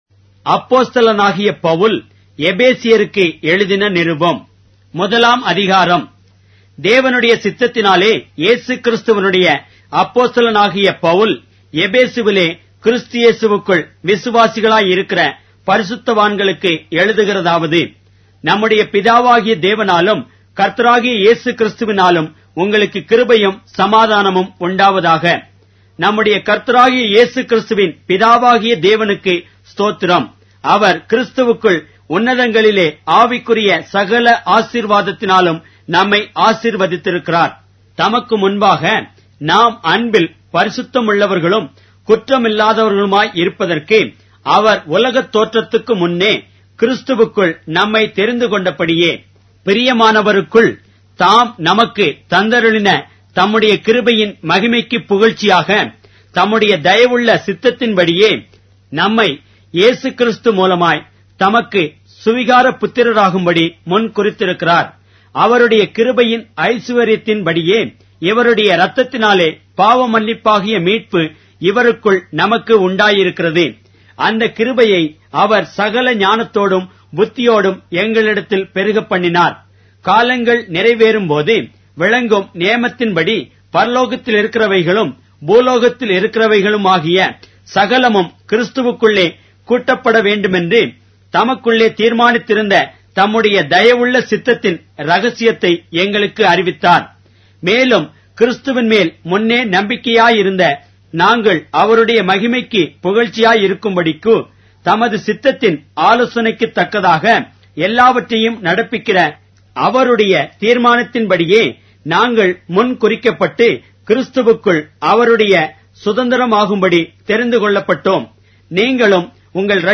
Tamil Audio Bible - Ephesians 5 in Tev bible version